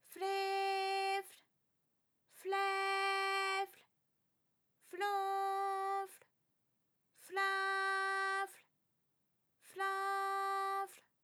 ALYS-DB-002-FRA - First publicly heard French UTAU vocal library of ALYS